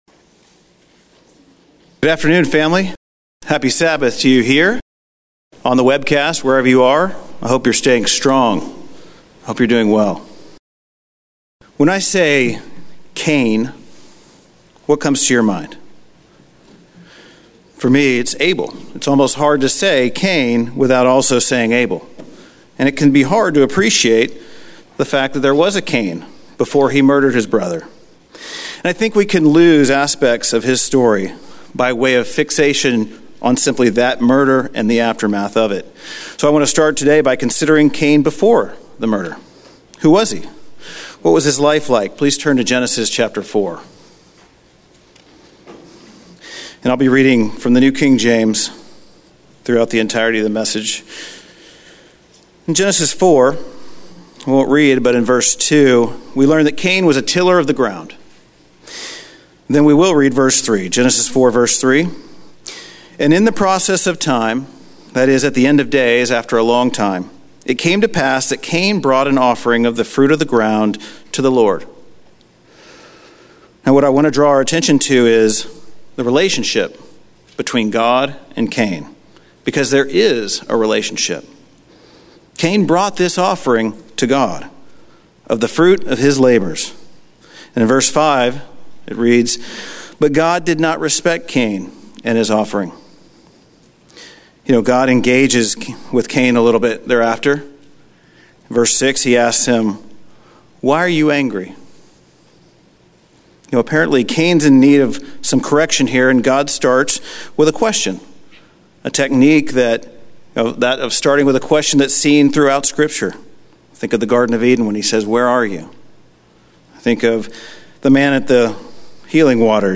Given in Houston, TX
split sermon